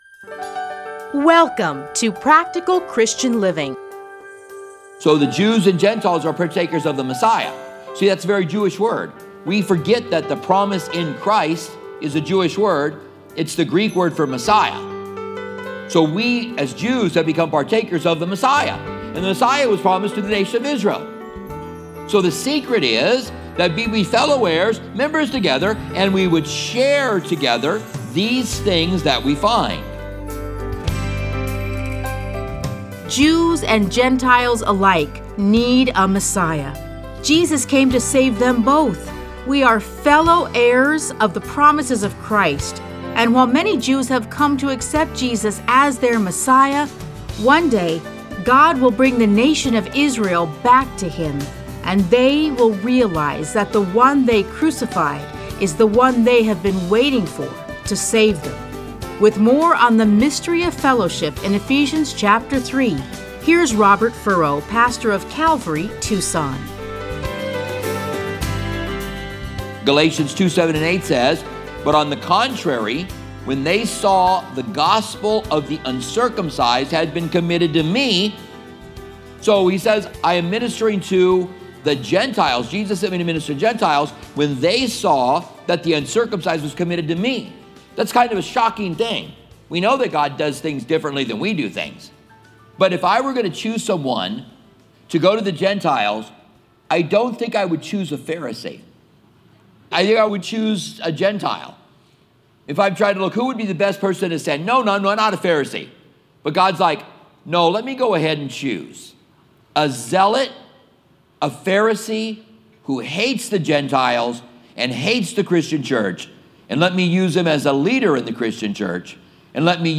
A Study in Ephesians 3:1-13